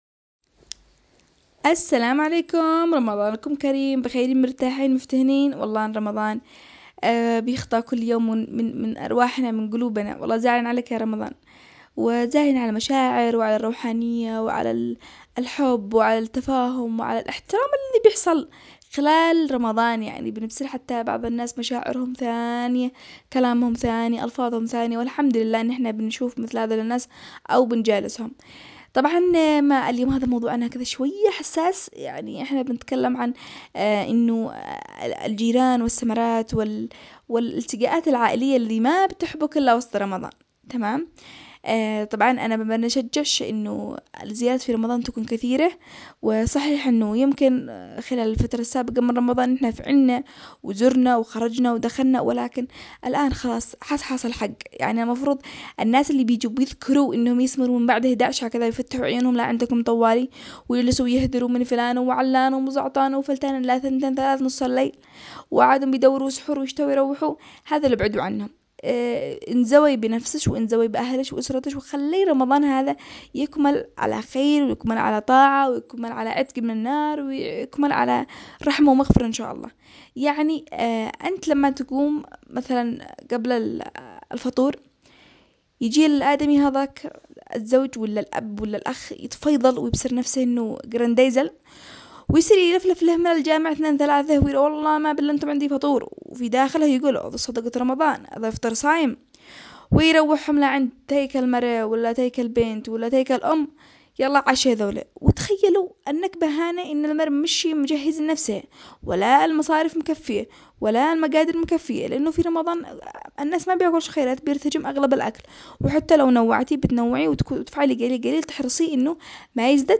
برنامج زنجبيل بغباره برنامج أسبوعي اجتماعي يناقش القضايا الخاصة بالنساء بأسلوب كوميدي و باللهجة العامية الصنعانية